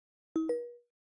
Голос Алисы от Яндекса